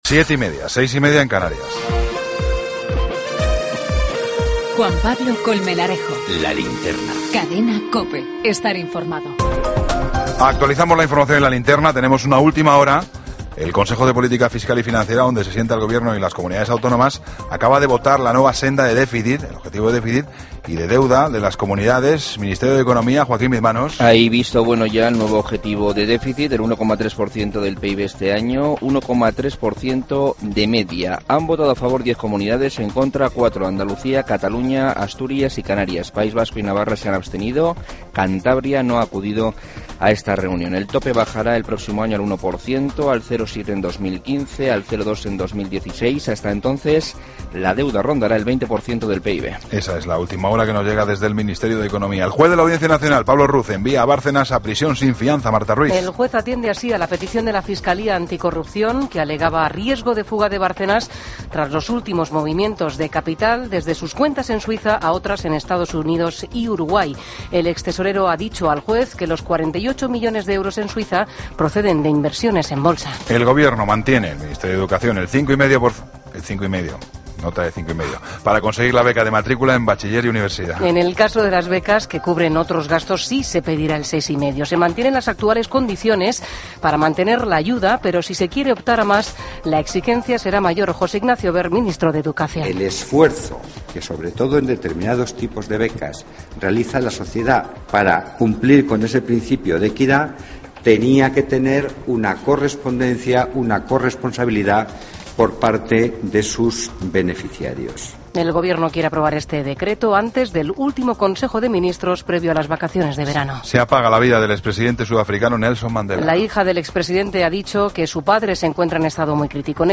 Toda la información con Juan Pablo Colmenarejo. Ronda de corresponsales. Entrevista